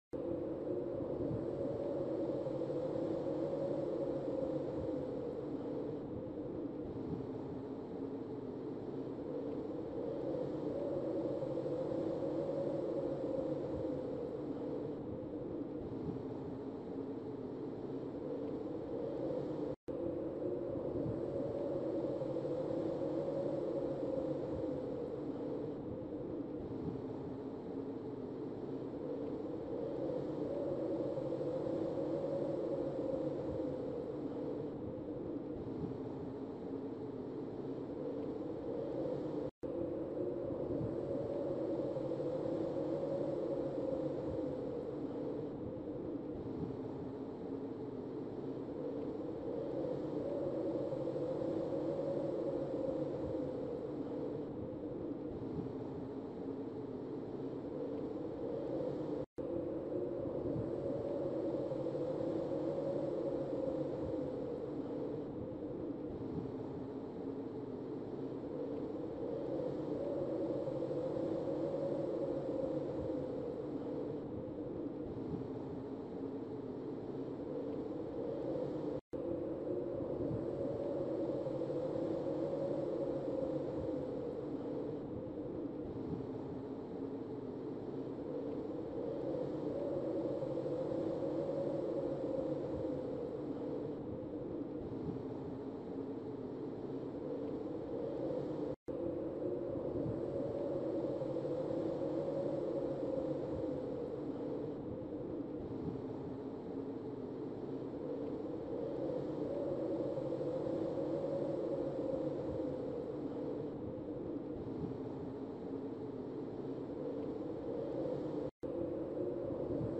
جلوه های صوتی
دانلود صدای برف از ساعد نیوز با لینک مستقیم و کیفیت بالا